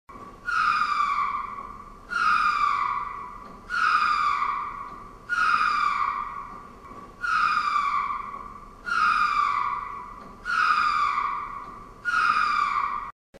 Звуки лемура
Крик среди деревьев